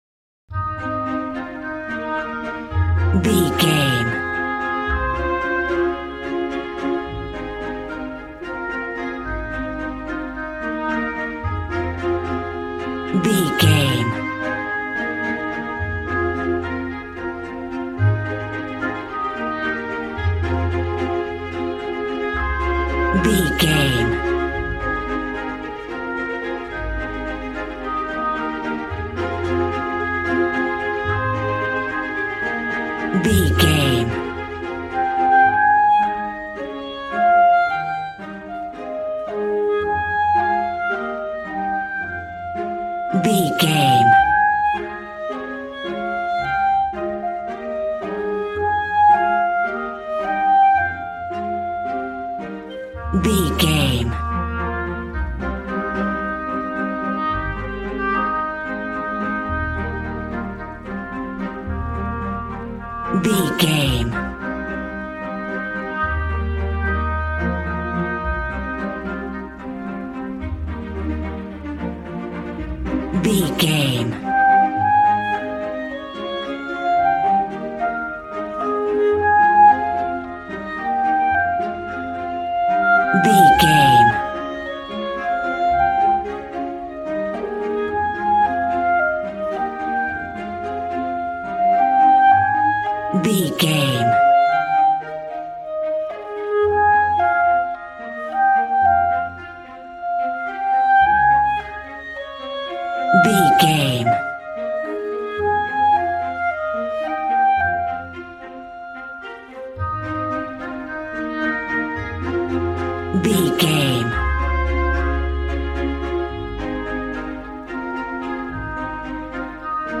A warm and stunning piece of playful classical music.
Regal and romantic, a classy piece of classical music.
Ionian/Major
A♭
regal
piano
violin
strings